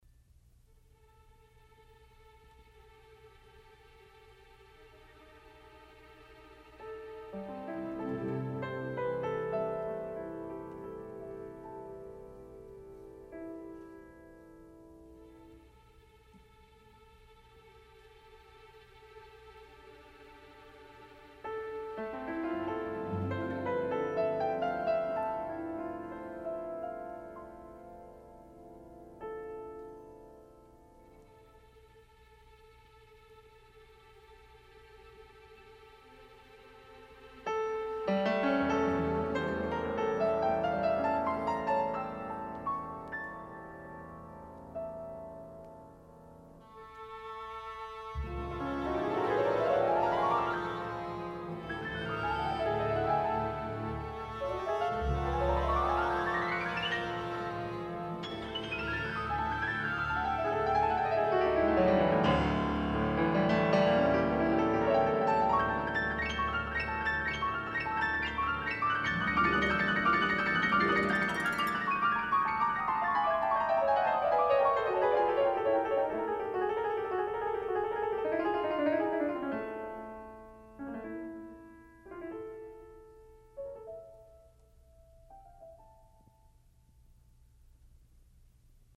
中板，钢琴以华彩表现主题，竖琴滑奏